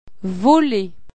Le conseiller juridique   tee pruhksâ ch'bap